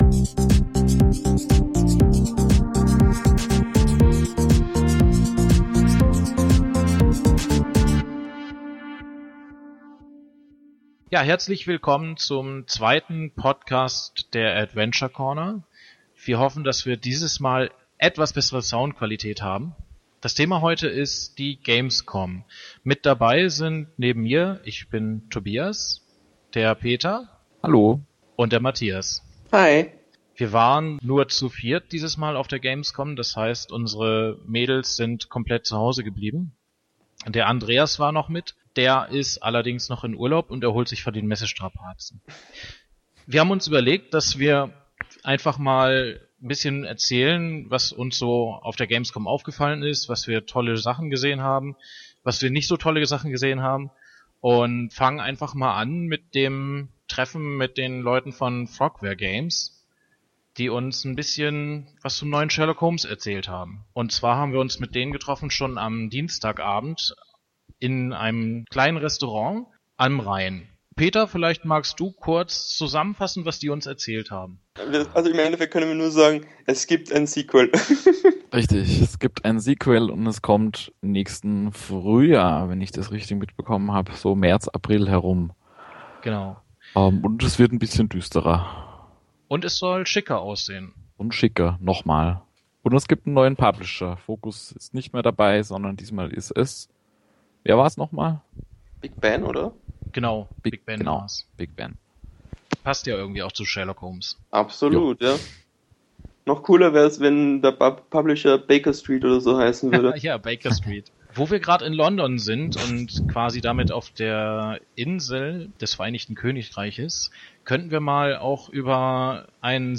Diesmal haben wir sogar eine bessere Tonqualität hinbekommen.